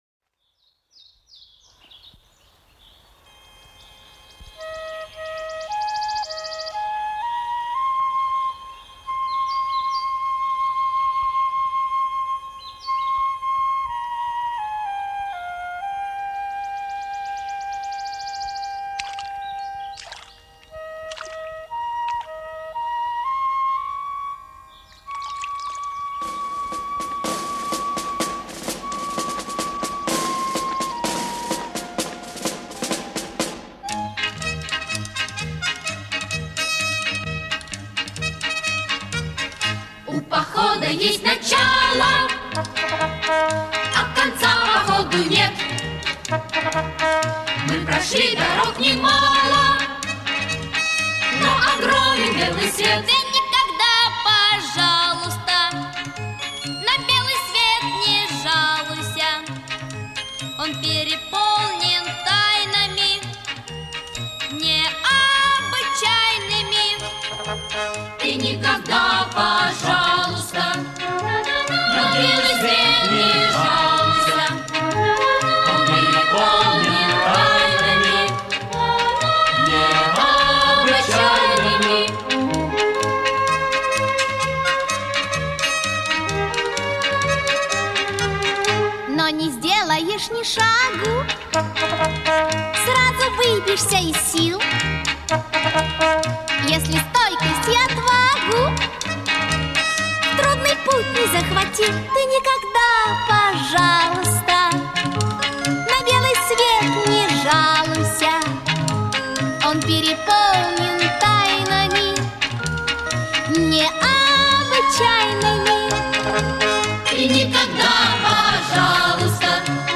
Из звуковой дорожки фильма